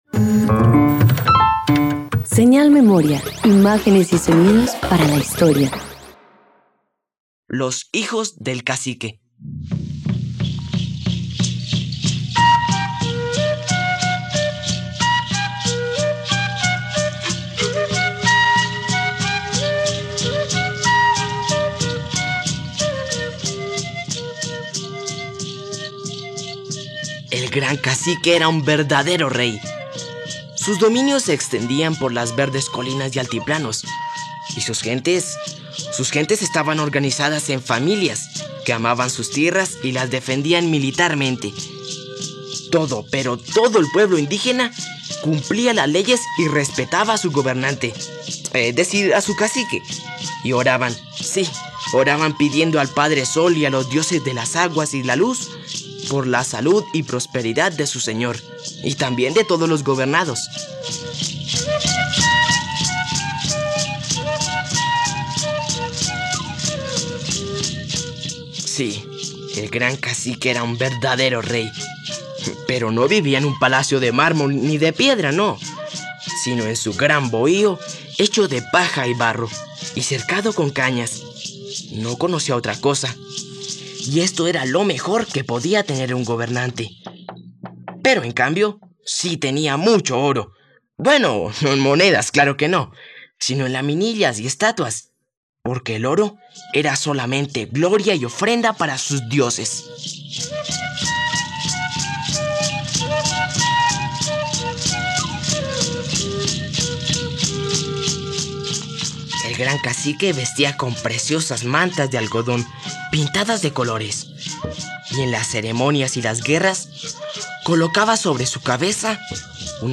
..Radioteatro. Escucha ‘Los hijos del cacique’, una obra original de José Agustín Pulido Téllez sobre viajes espaciales.